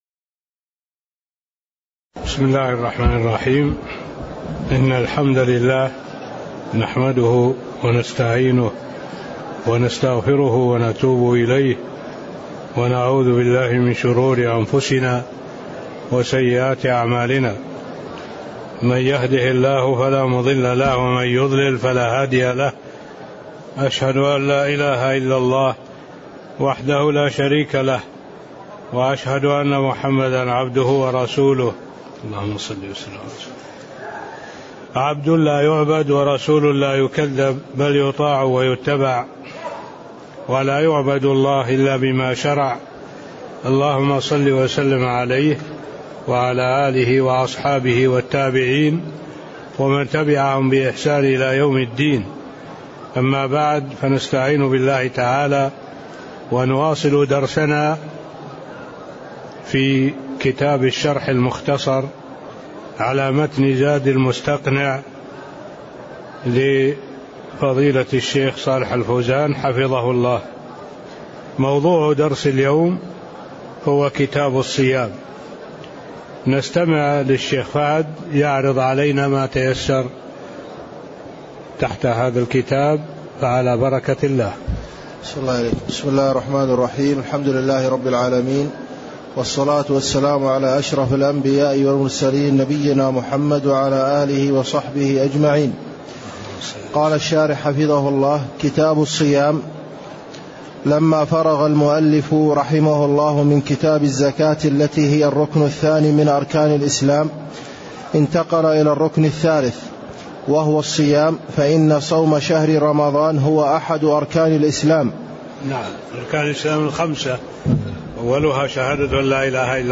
تاريخ النشر ١ شعبان ١٤٣٤ هـ المكان: المسجد النبوي الشيخ: معالي الشيخ الدكتور صالح بن عبد الله العبود معالي الشيخ الدكتور صالح بن عبد الله العبود المقدمة (01)